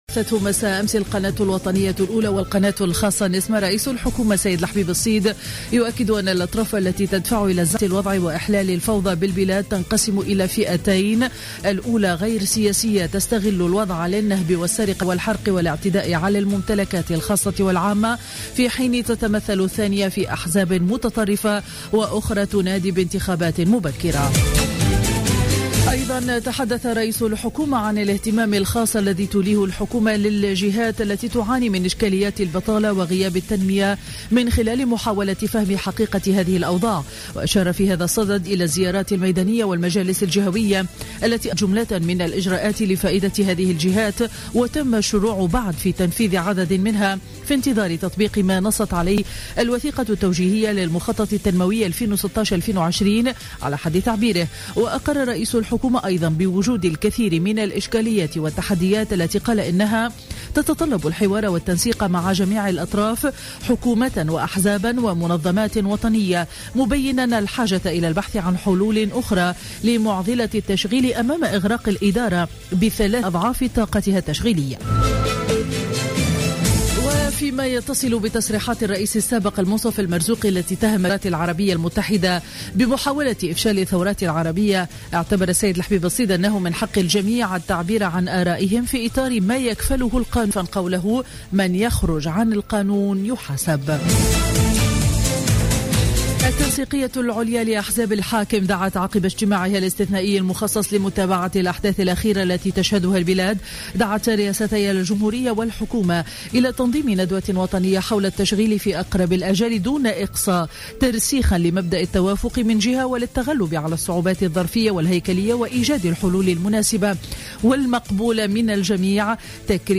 نشرة أخبار السابعة صباحا ليوم الأحد 24 جانفي 2016